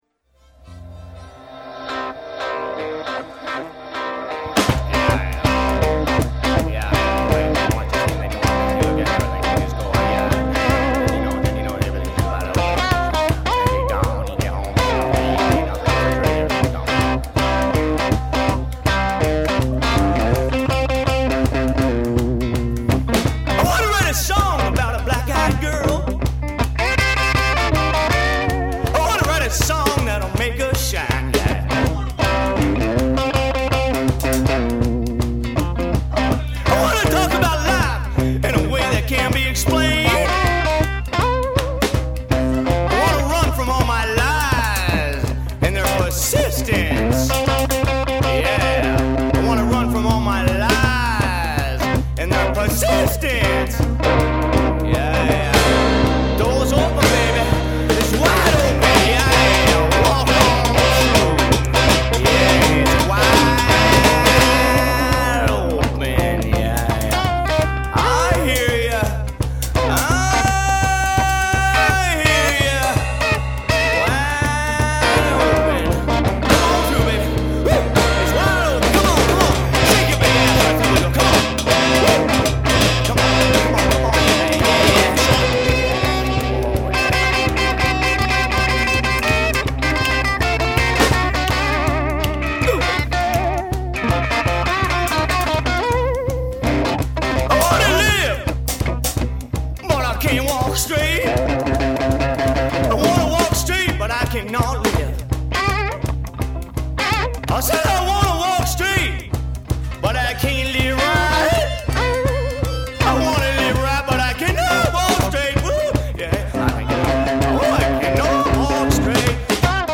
rockabilly, punk, rock garage et blues